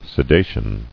[se·da·tion]